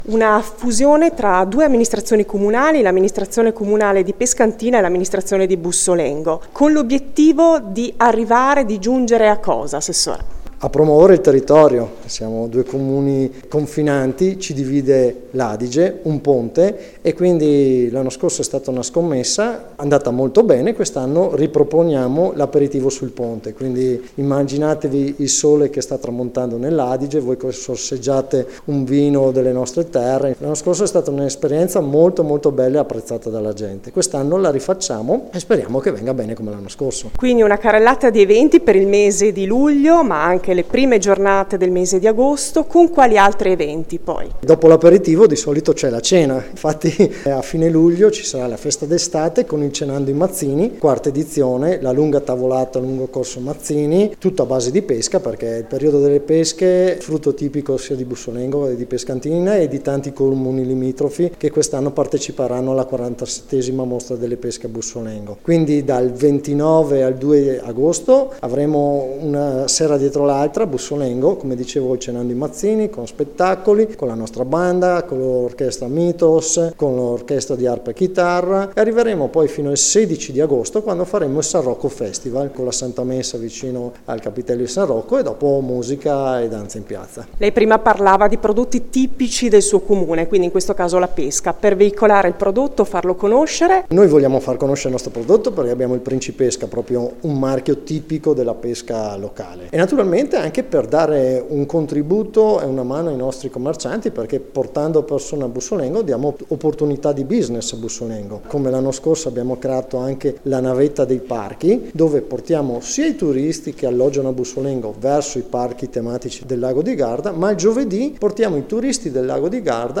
L’assessore alla Promozione del territorio e alle Manifestazioni Massimo Girelli:
Interviste